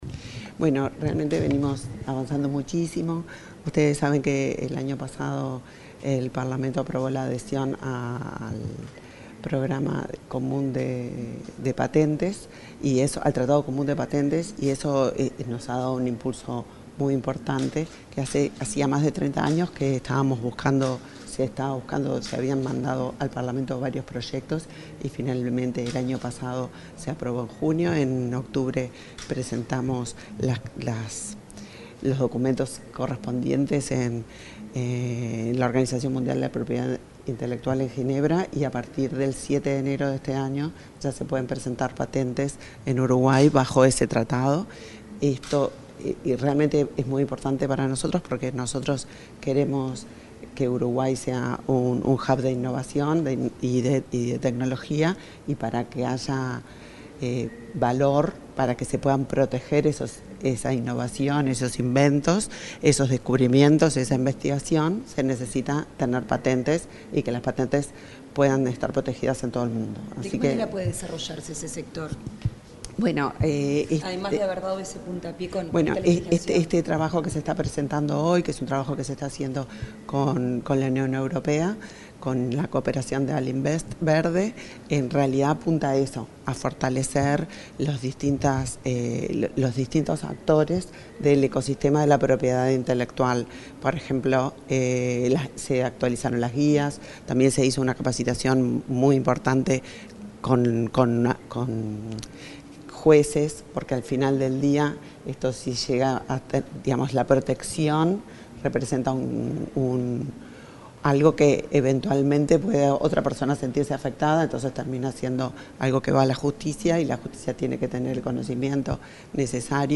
Declaraciones de la ministra de Industria, Elisa Facio
Declaraciones de la ministra de Industria, Elisa Facio 19/02/2025 Compartir Facebook X Copiar enlace WhatsApp LinkedIn Este miércoles 19, en Montevideo, el embajador de la delegación de la Unión Europea en Uruguay, Paolo Berizzi, y la ministra de Industria, Elisa Facio, participaron en un desayuno de trabajo acerca de la cooperación entre nuestro país y el mencionado ámbito en materia de propiedad industrial. Luego, Facio dialogó con la prensa.